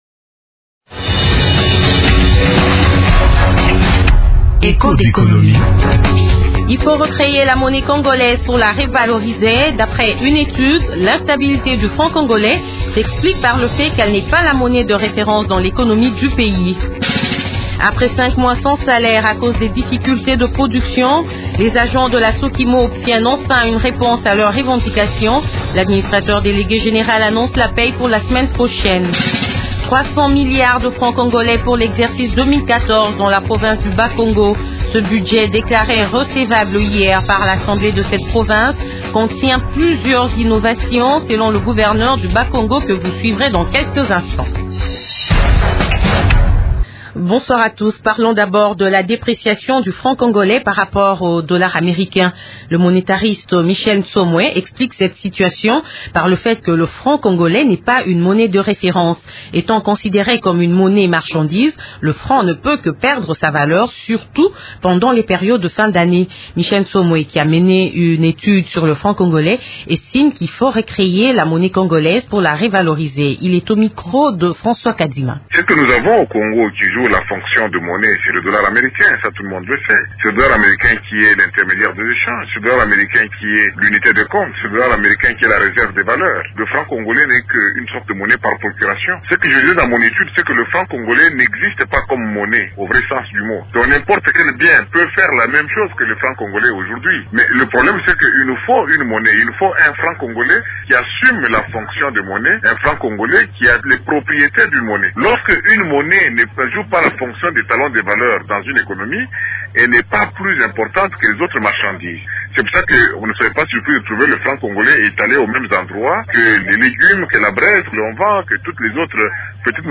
une interview à Radio Okapi